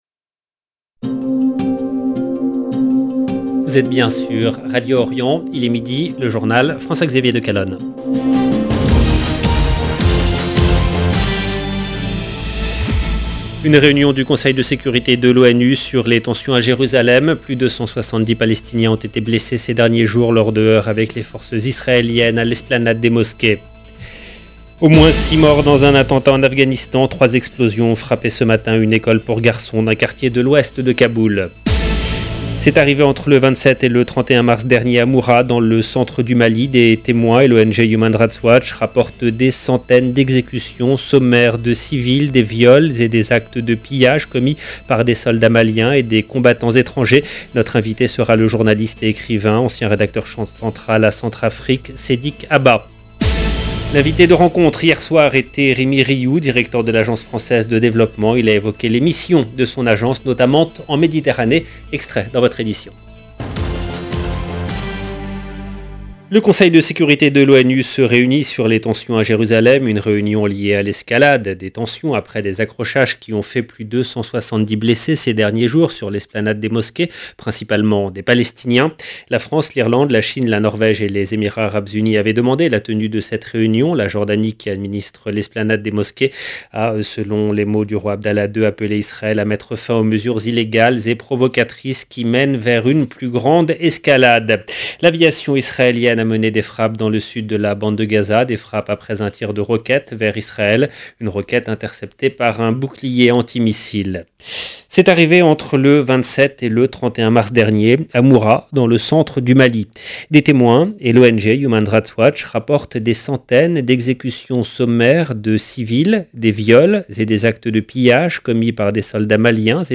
LE JOURNAL DE MIDI EN LANGUE FRANCAISE DE MIDI DU 19/04/22 LB JOURNAL EN LANGUE FRANÇAISE